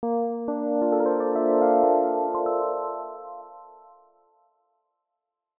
描述：电吉他上重复的琶音，圆润而梦幻的长序列。用Motu 828mk2在Soundforge上录制。
标签： 琶音 梦幻 吉他 醇厚 重复 重复
声道立体声